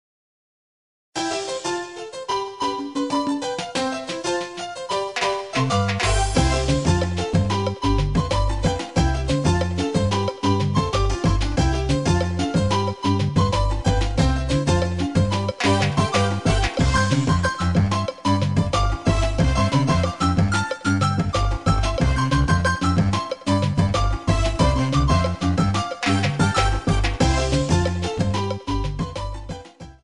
Title music
4=Cut off and fade-out }} Category